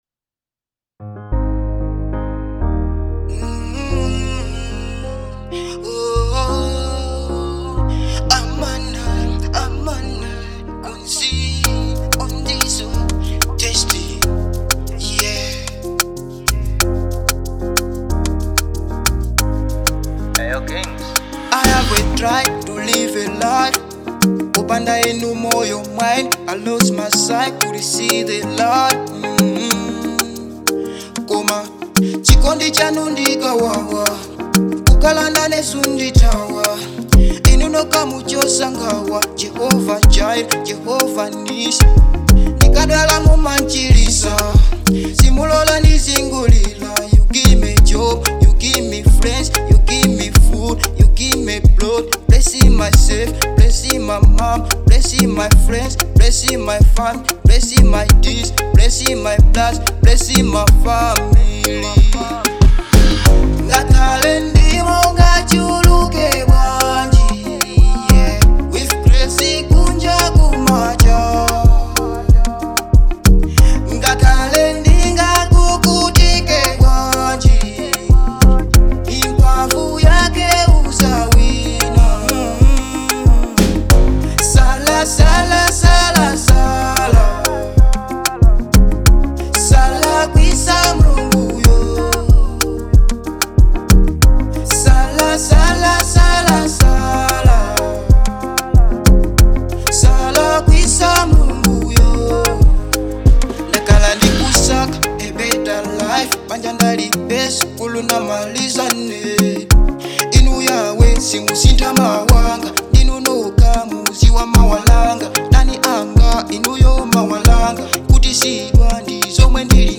Afro-Gospel